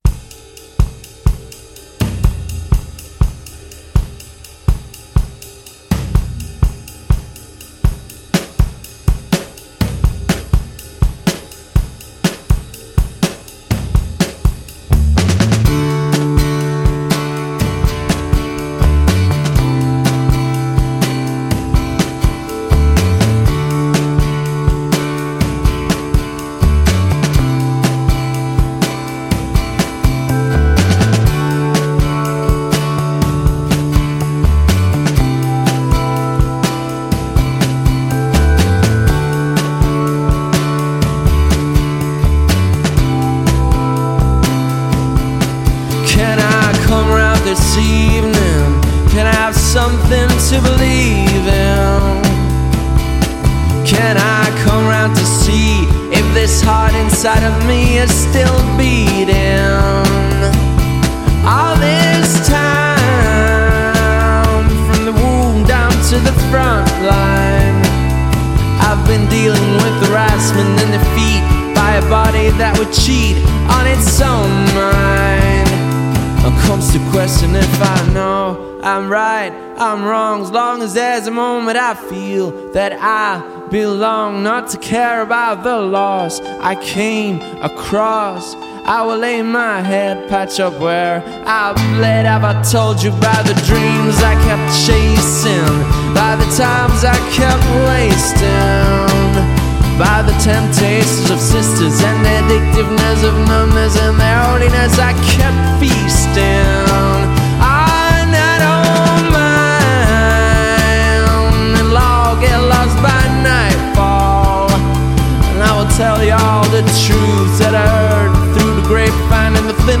راک Rock